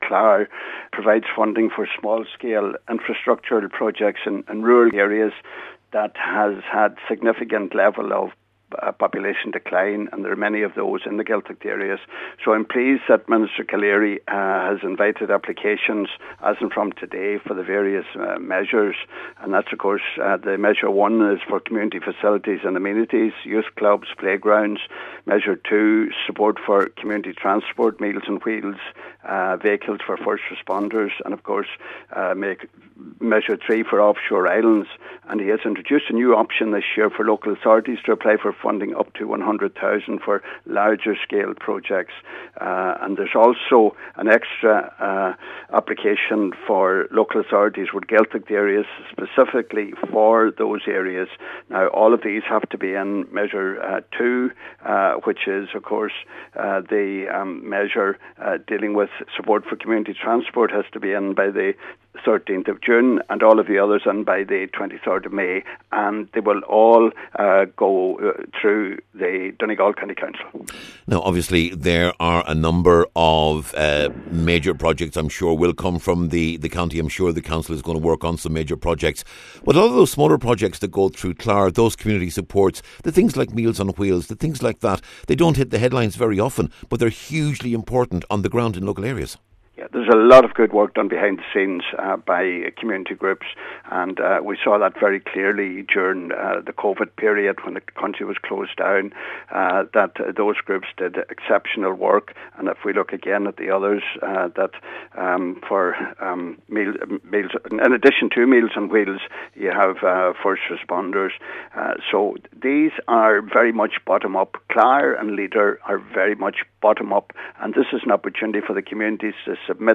Donegal TD Pat The Cope Gallagher says CLAR continues to be a very important funding source for local communities, particularly in Gaeltacht areas………